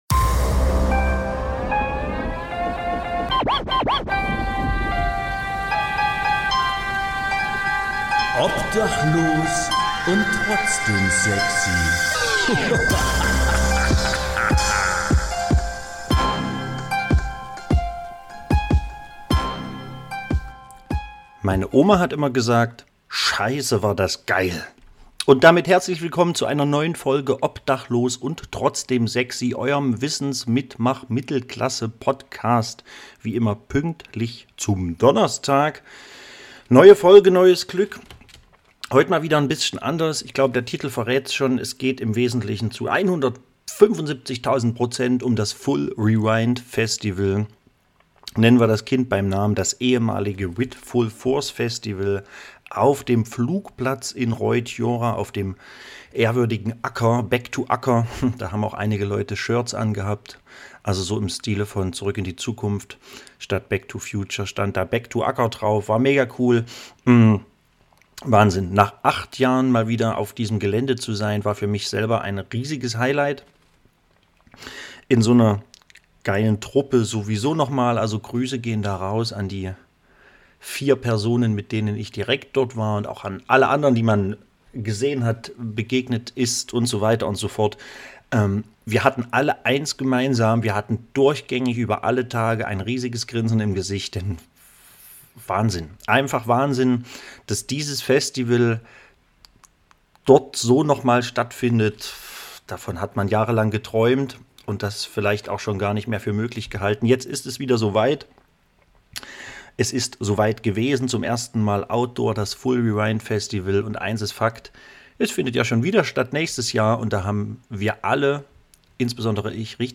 Heute zu Gast: Niemand, aber mit Interviews!